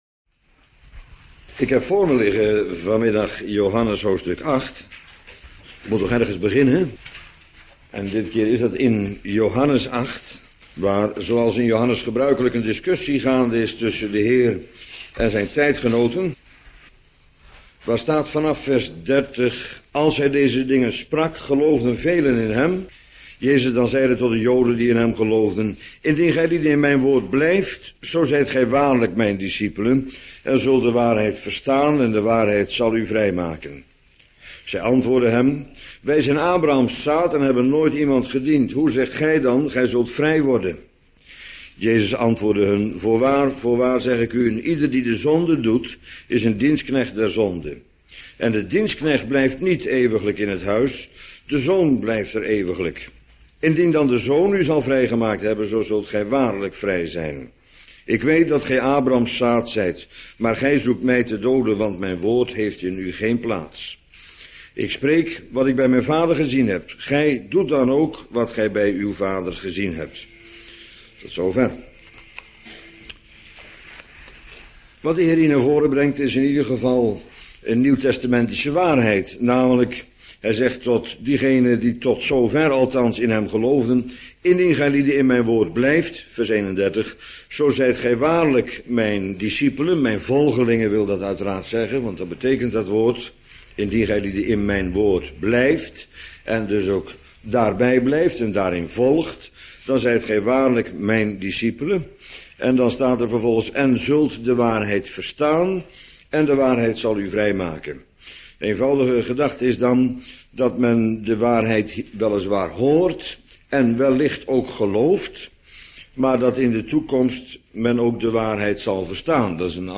Bijbelstudie